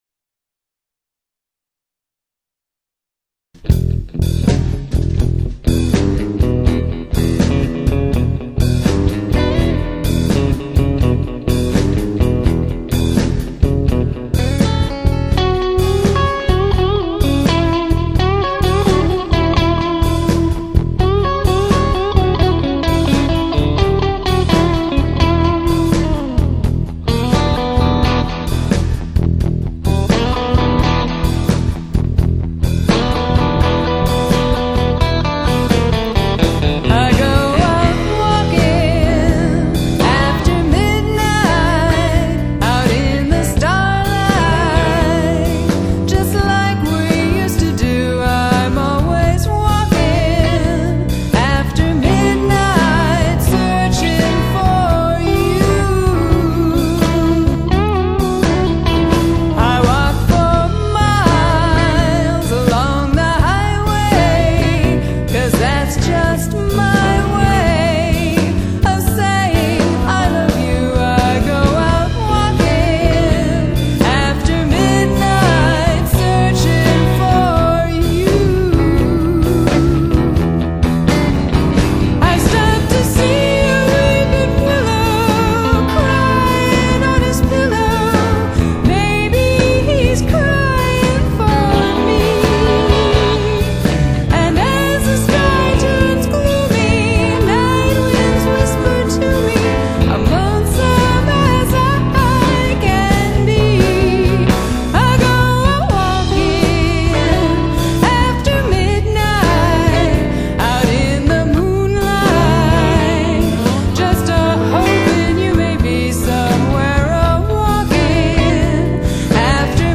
This is the ultimate variety band.